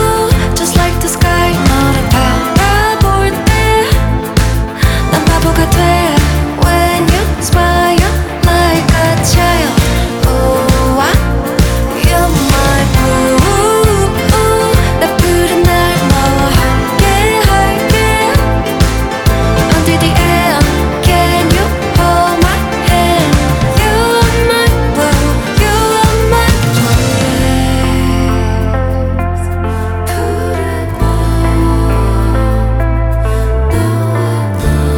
Жанр: Поп музыка / Рок
K-Pop, Pop, Rock